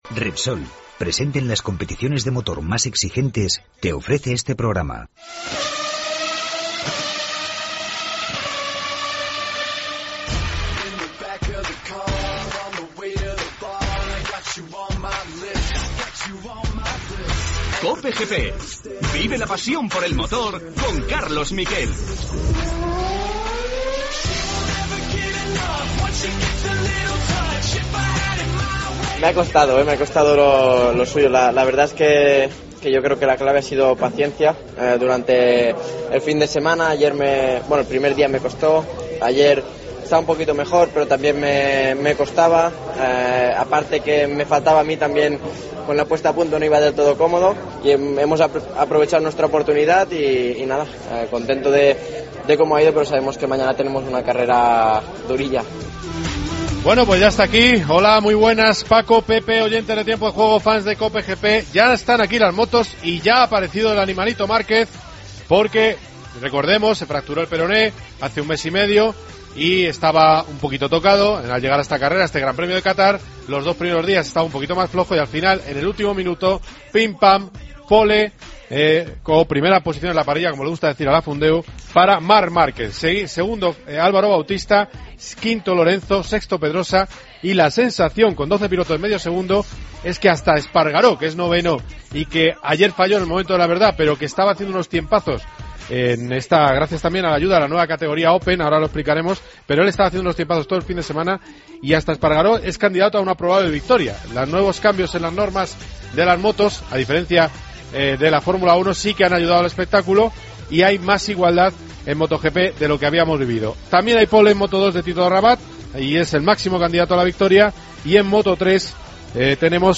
AUDIO: Analizamos el comienzo del Mundial de motociclismo. Escuchamos a Márquez, Lorenzo, Bautista y Pedrosa. Entrevista a Jorge Martínez Aspar.